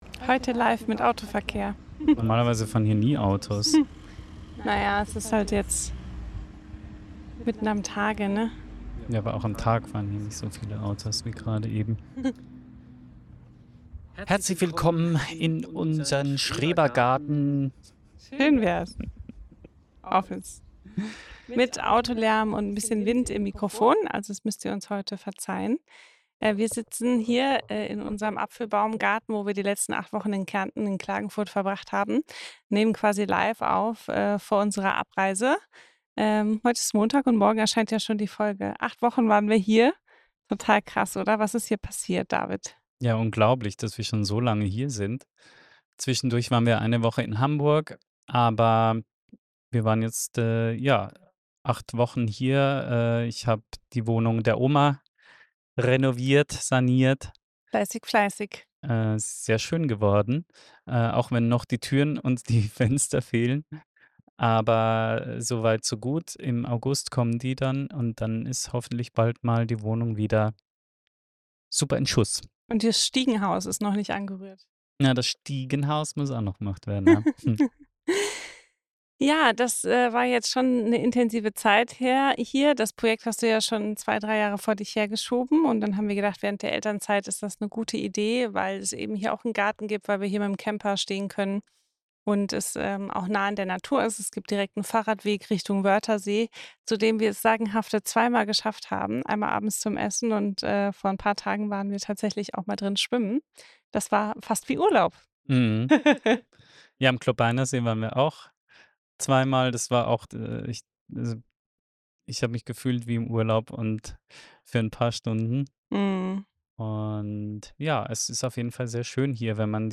Kurzinterview